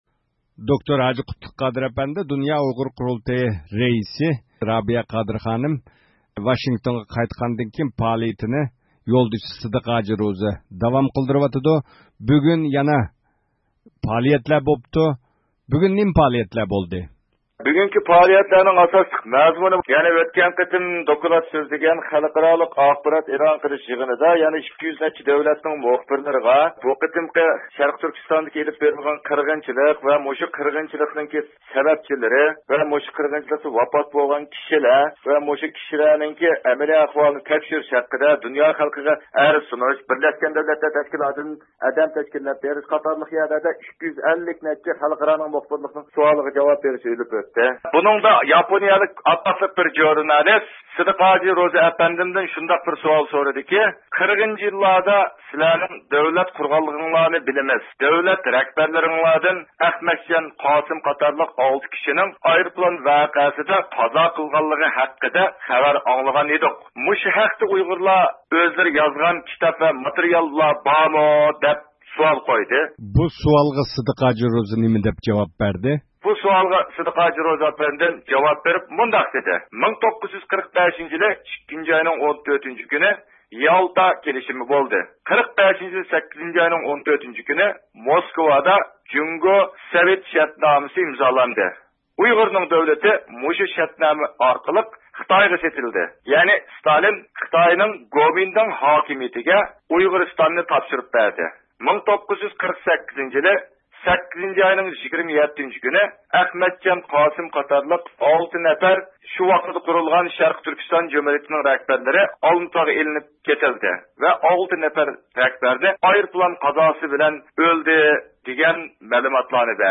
تېلېفون سۆھبىتى ئېلىپ باردۇق.